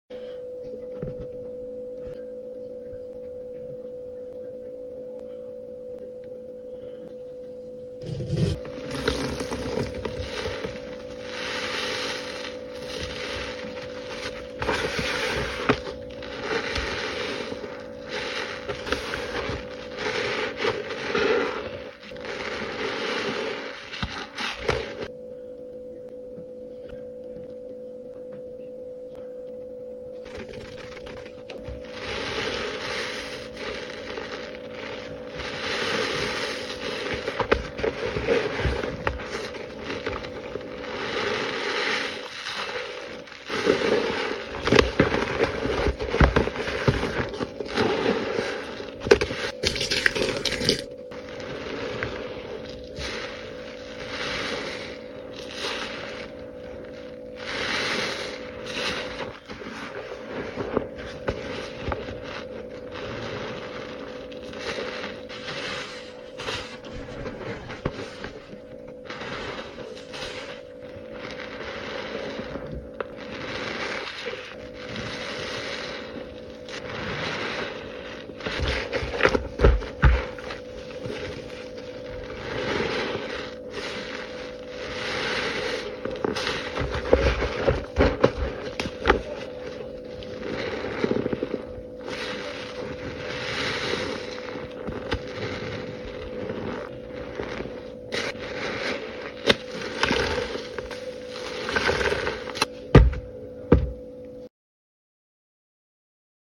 pls ignore the first squeeze idk why they keep f*cking up!!🙄😳 but we got there in the end!!!